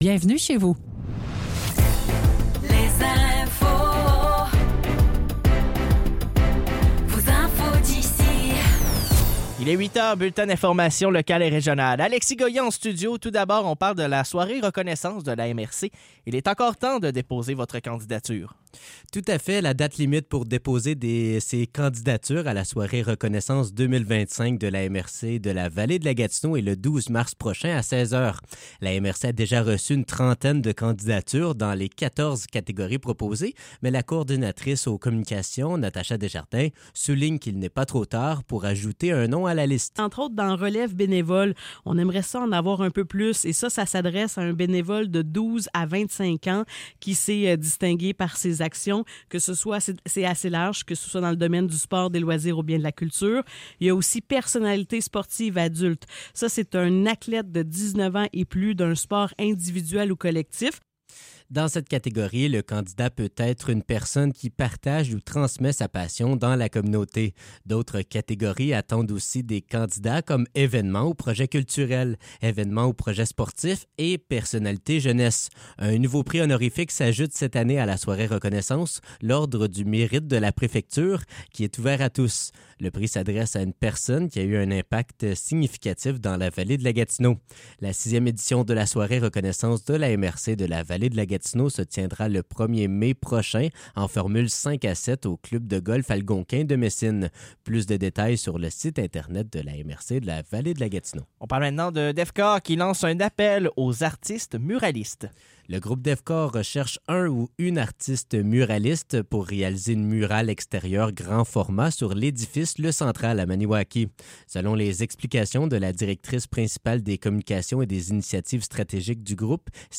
Nouvelles locales - 7 mars 2025 - 8 h